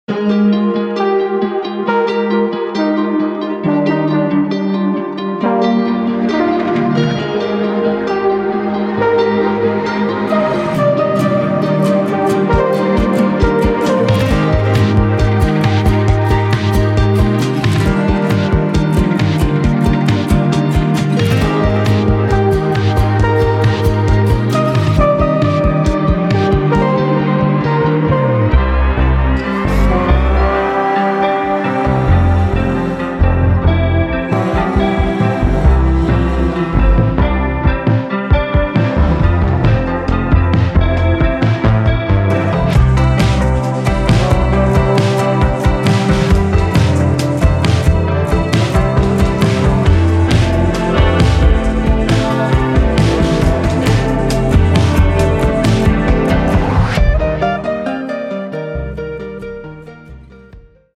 Shoegaze